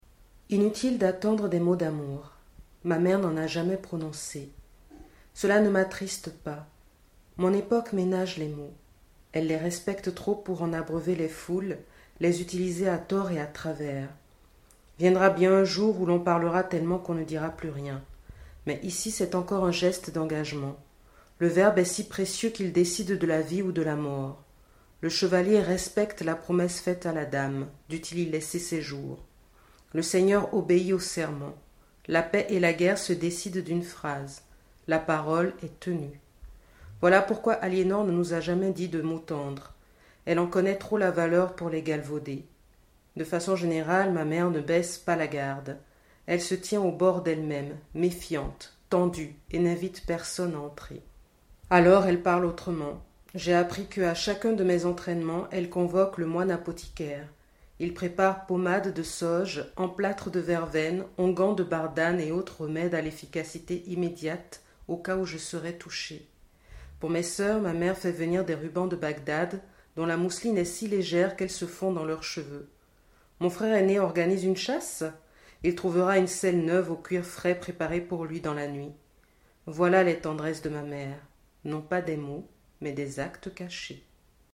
Plutôt que de les reproduire, je vous les lis :